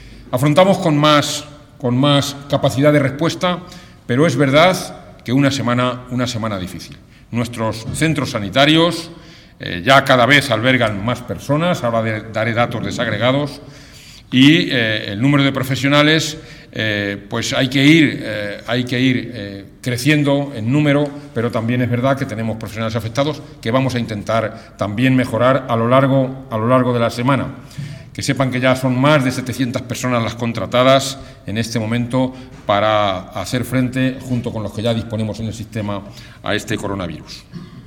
(DIRECTO) Comparecencia informativa videoconferencia mantenida con los gerentes de los hospitales dependientes del SESCAM